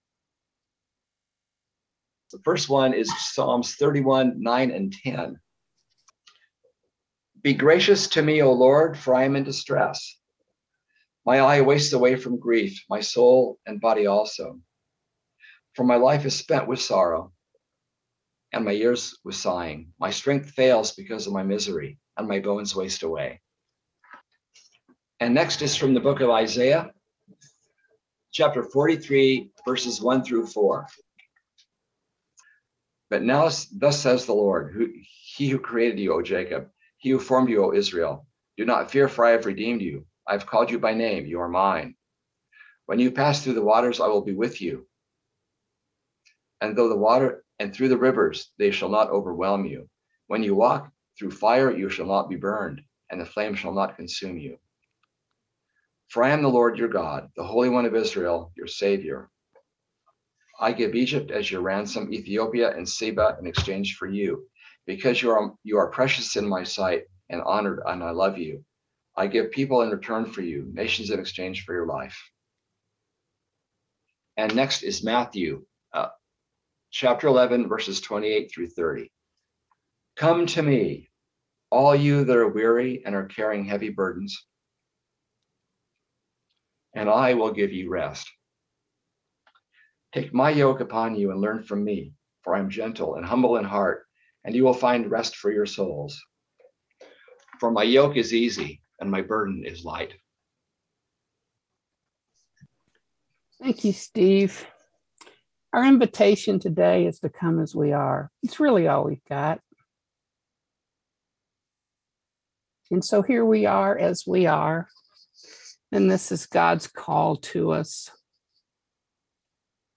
Listen to the most recent message from Sunday worship at Berkeley Friends Church, “Come As You Are!”